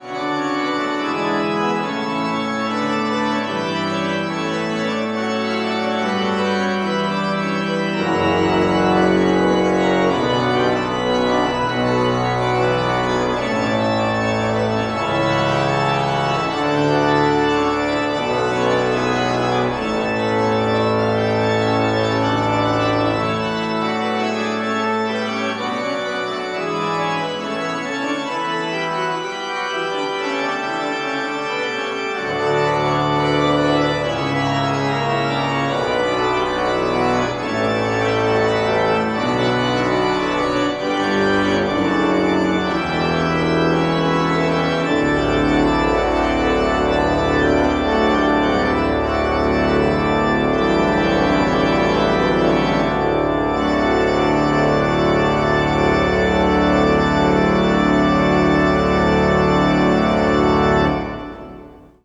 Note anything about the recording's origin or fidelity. Tetrahedral Ambisonic Microphone Recorded December 12, 2009, in the Bates Recital Hall at the Butler School of Music of the University of Texas at Austin.